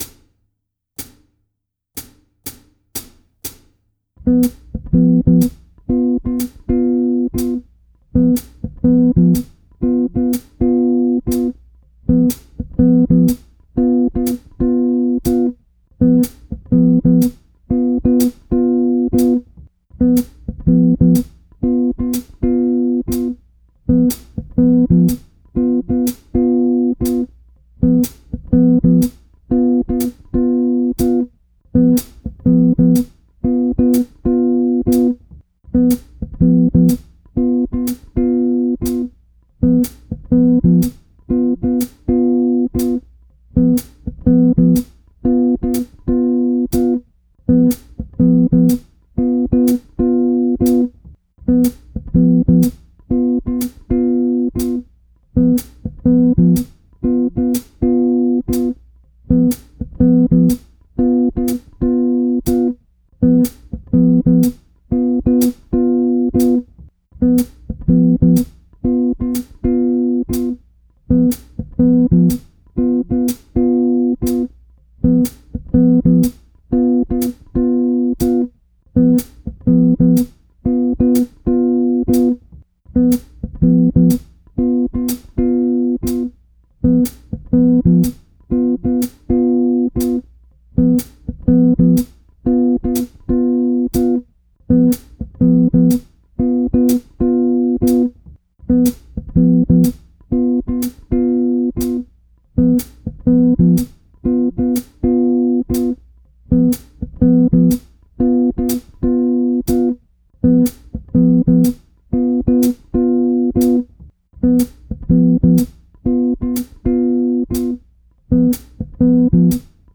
EXAMPLE 3 – This is the backing track without bass so you can practice your walking lines too!
Backing-Track-No-Bass.wav